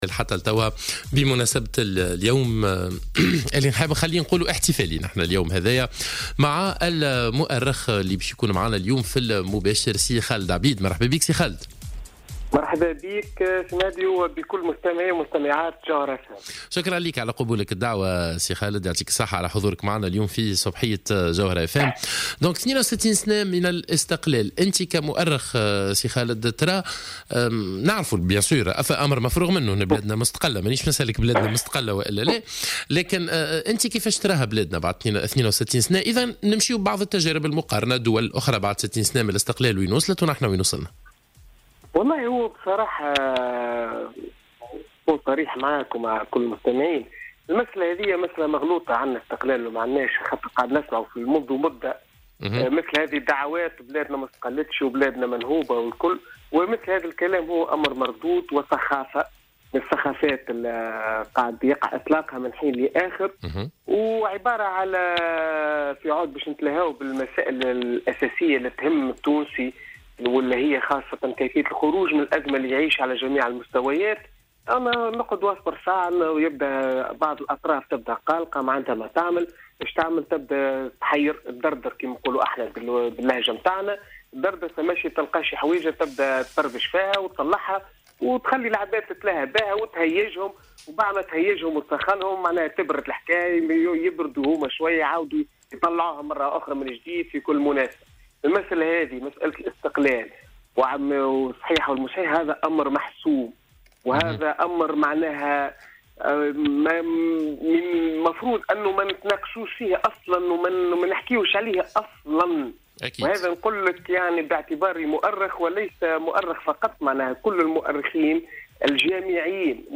في مداخلة له على الجوهرة "اف ام"